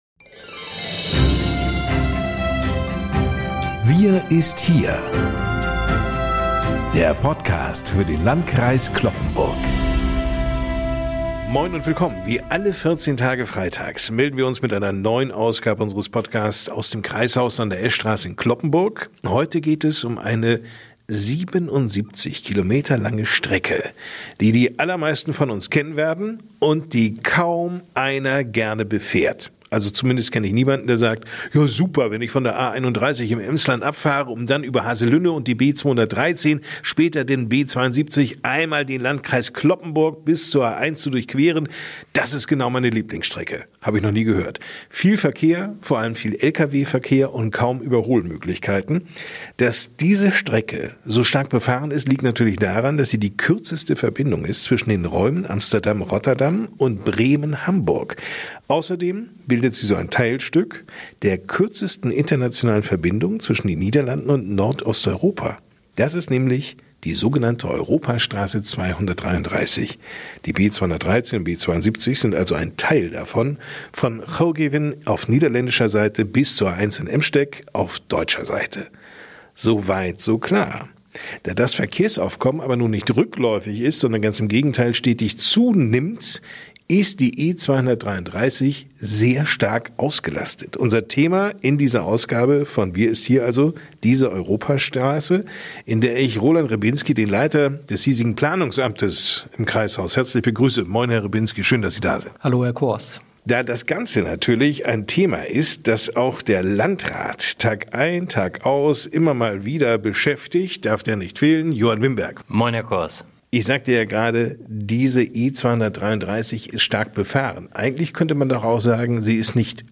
In der neuen Episode von WIR ST HIER für den Landkreis Cloppenburg diskutieren